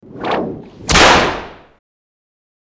Peitsche
Peitsche.mp3